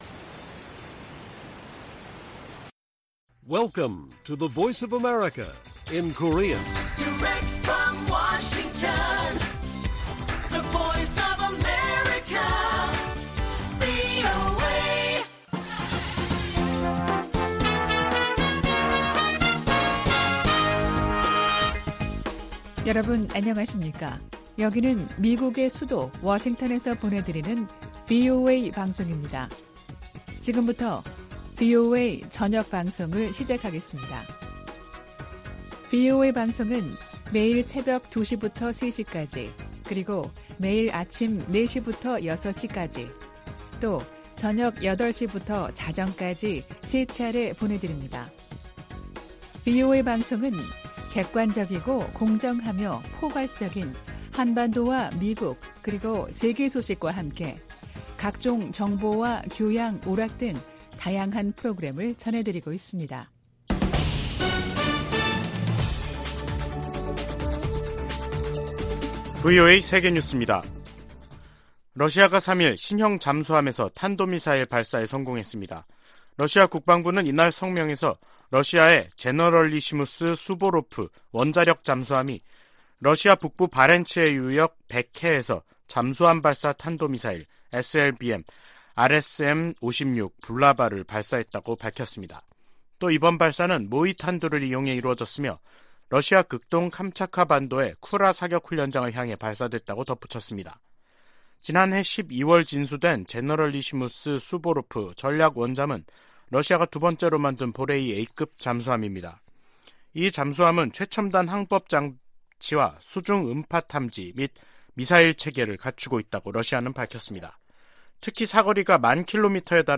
VOA 한국어 간판 뉴스 프로그램 '뉴스 투데이', 2022년 11월 4일 1부 방송입니다. 북한 김정은 정권이 오늘 대규모 군용기를 동원한 무력 시위를 하자 한국이 이에 대응해 스텔스 전투기 등 80여를 출격시키는 등 한반도에서 긴장이 계속되고 있습니다. 미국과 한국 국방장관이 미국 전략자산을 적시에 한반도 전개하는 방안을 강구하고 핵우산 훈련도 매년 실시하기로 합의했습니다.